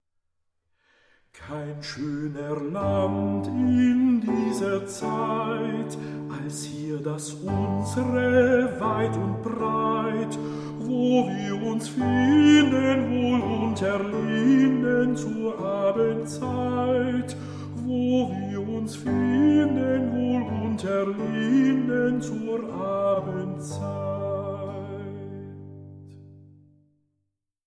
Deutsche Volkslieder